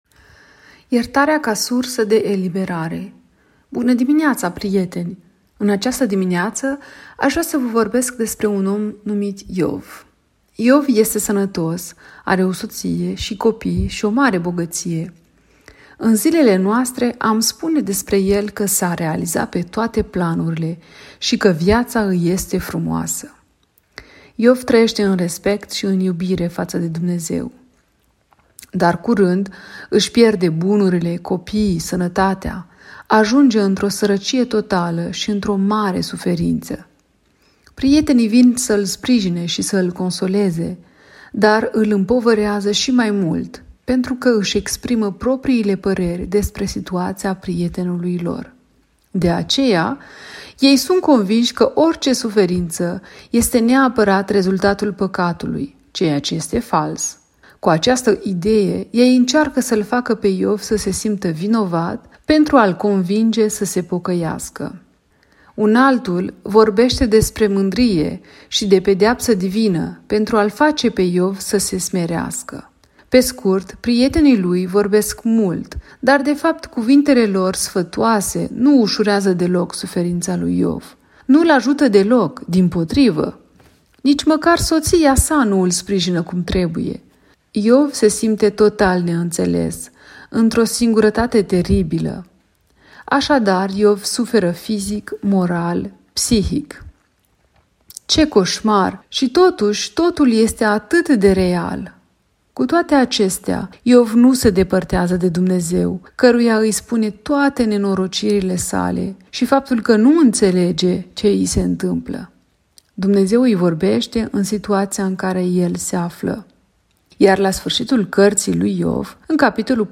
Meditatie "Iertarea un izvor de eliberare"